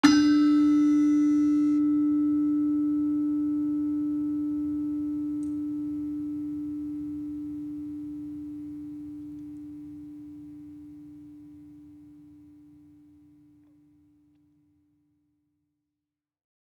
Gamelan Sound Bank
Gender-4-D3-f.wav